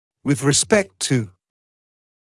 [wɪð rɪ’spekt tuː][уиз ри’спэкт туː]по отношению к